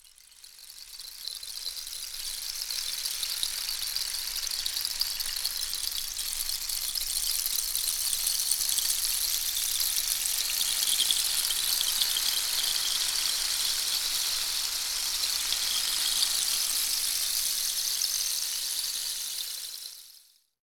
PRC RAIN 00L.wav